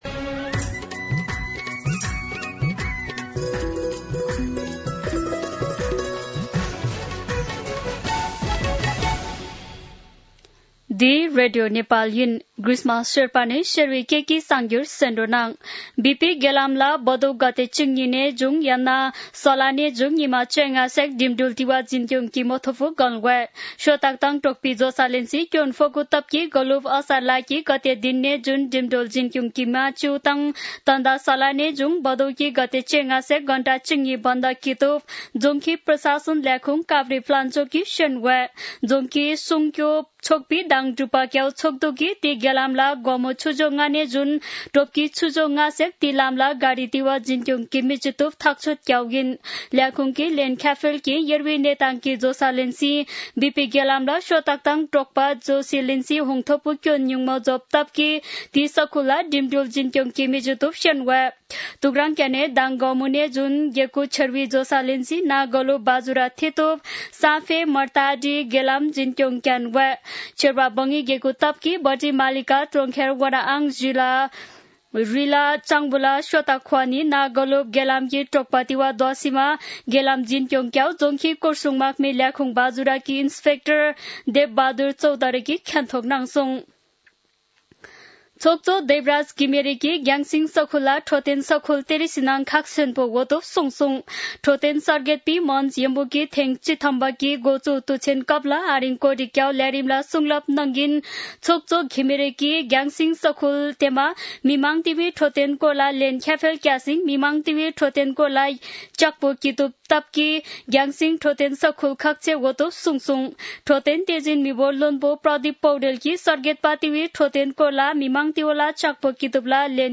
शेर्पा भाषाको समाचार : ३१ साउन , २०८२
Sherpa-News-04-31.mp3